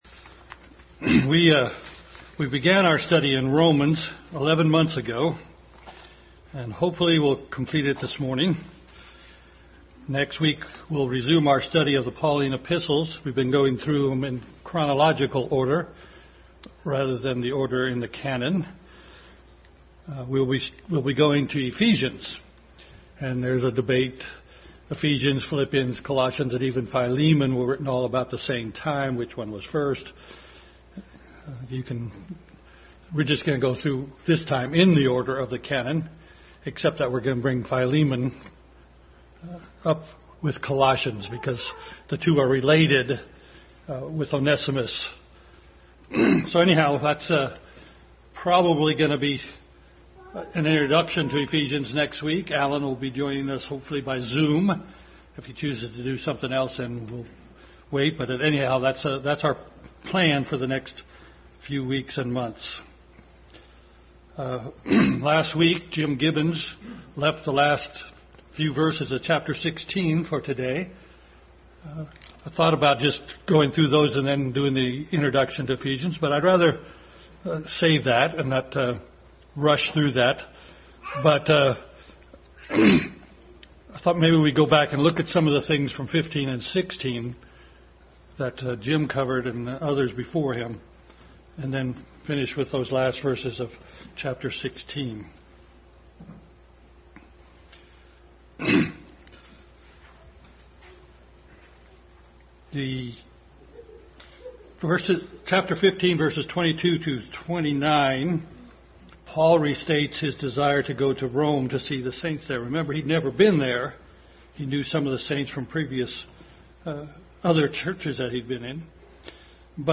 Adult Sunday School Class final study in the book of Romans series.
Romans 16:21-27 Service Type: Sunday School Adult Sunday School Class final study in the book of Romans series.